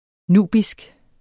Udtale [ ˈnuˀbisg ]